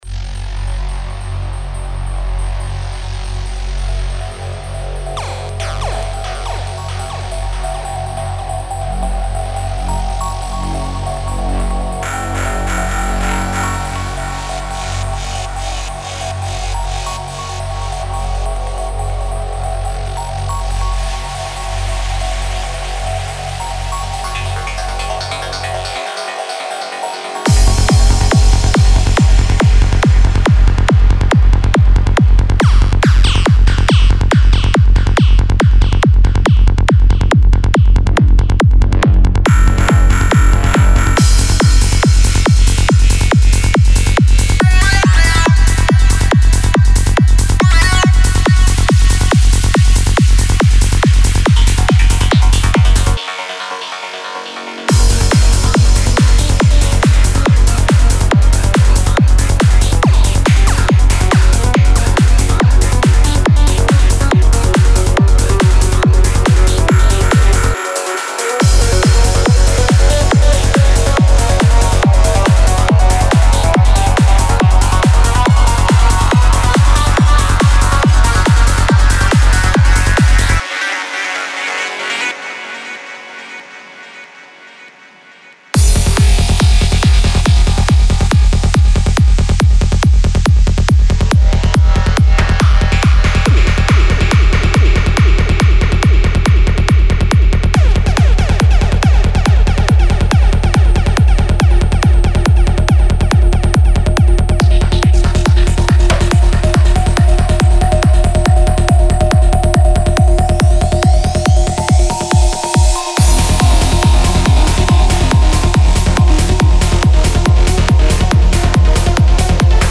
Type: Serum
Mixdown Of Demo.